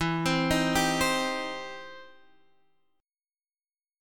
Cm/E chord